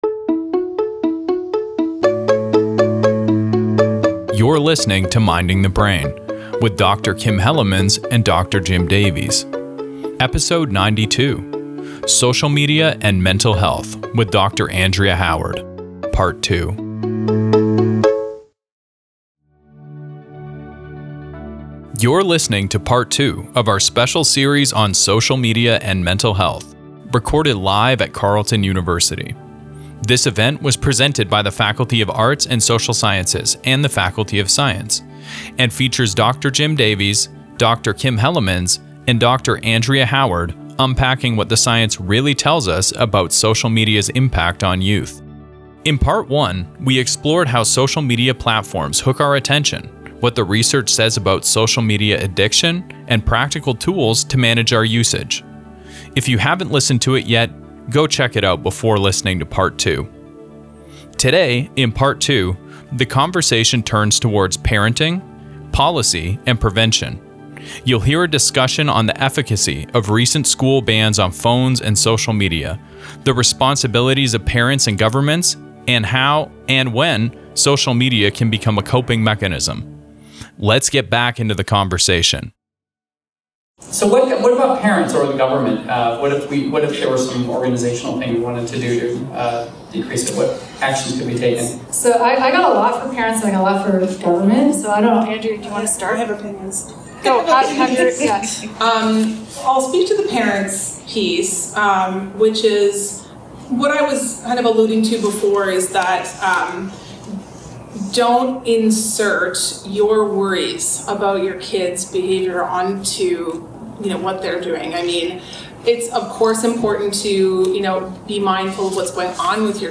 This is a live recording of Beyond the Headlines: Social Media, Youth and the Science of Well-Being, an event that was hosted by the Faculty of Arts and Social Sciences and the Faculty of Science on May 21, 2025, at Carleton University.